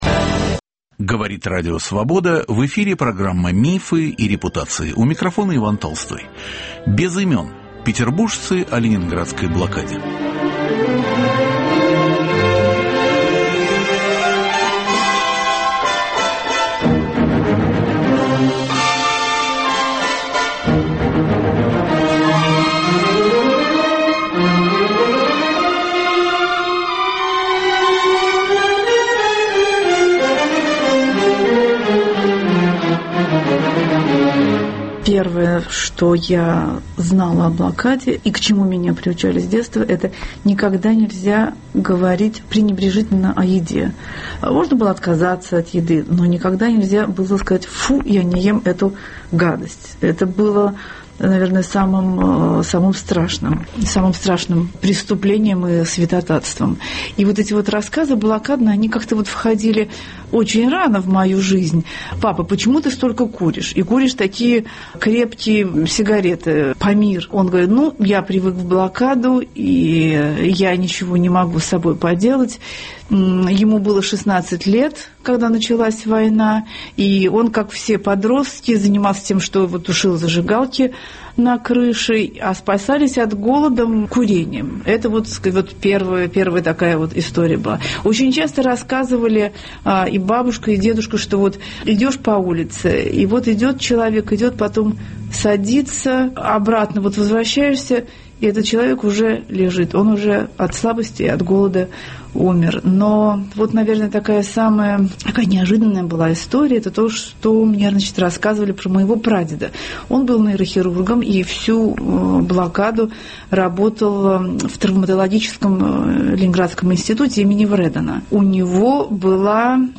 Ученый, врач, историк, студент, преподаватель, медсестра. Петербуржцы разных поколений - от 18 лет до 90 - делятся своими знаниями о блокаде. Одни пережили ее сами, другие слышали о ней рассказы родственников и знакомых.